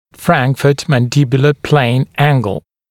[ˈfræŋkfət ˌmæn’dɪbjulə pleɪnz ‘æŋgl][ˈфрэнкфэт ˌмэн’дибйулэ плэйнз ‘энгл]угол между франкфуртской и нижнечелюстной плоскостями